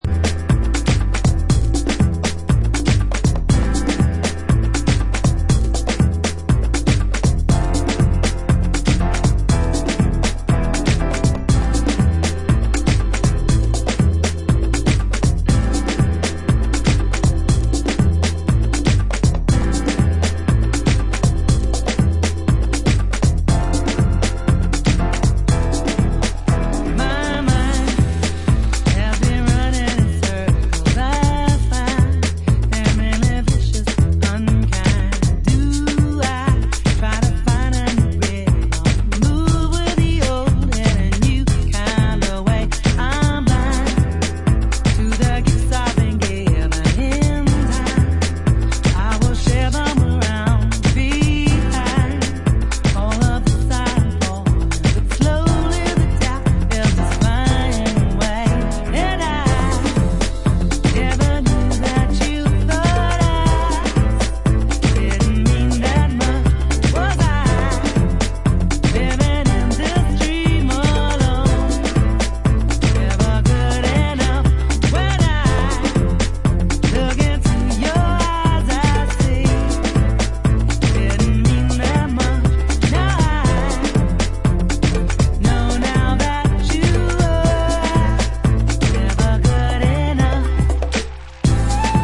atmospherically charged funky house version